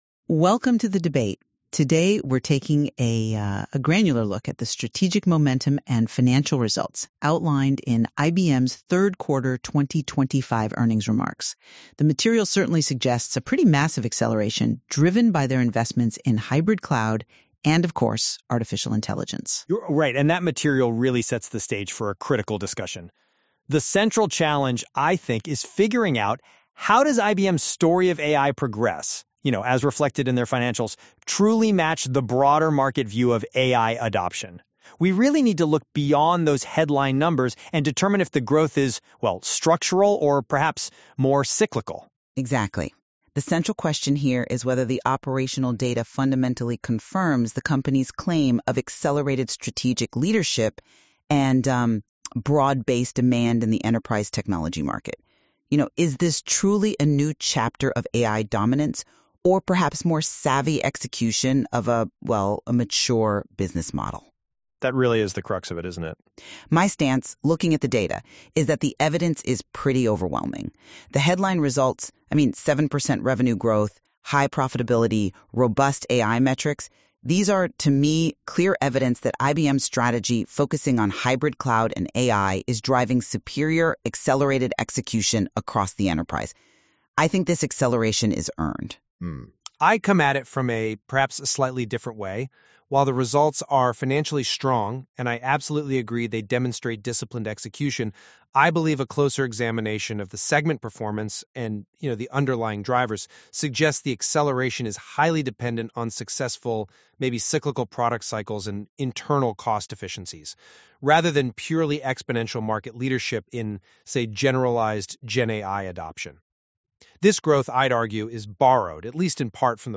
This audio analysis was generated by Google Gemini NotebookLM using a PDF of the earnings call transcript as the only source.